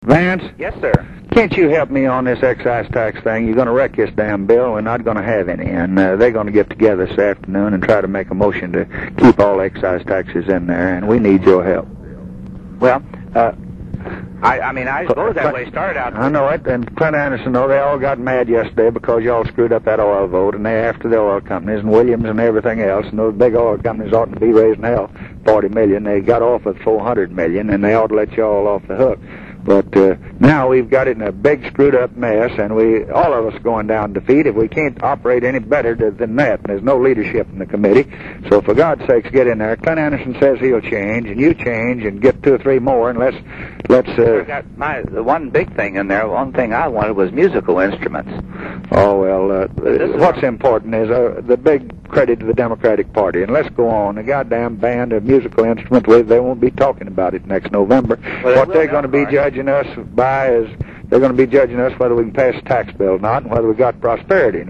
President Johnson and Indiana senator Vance Hartke, 9 Jan. 1964, discussing the fate of the administration’s tax bill